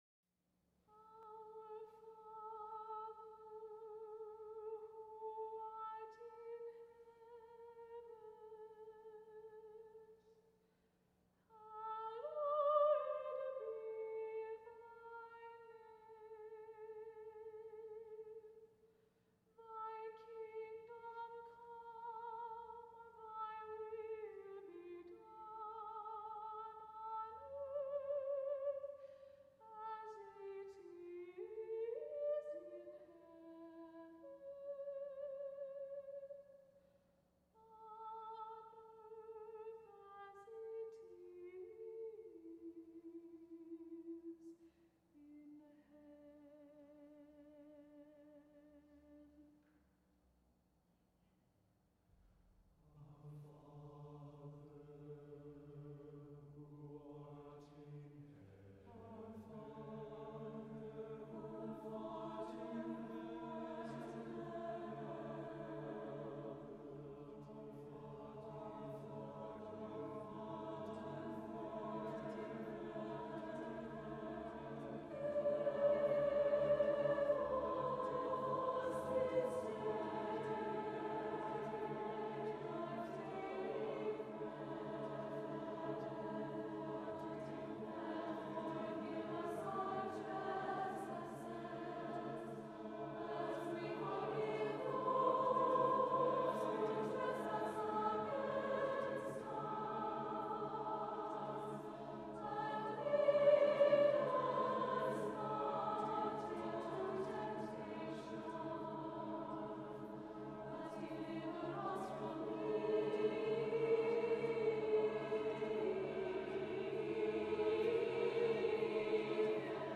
for SATB Chorus and Soprano Solo (1986)